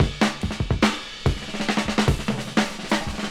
Crazy Fill.wav